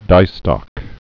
(dīstŏk)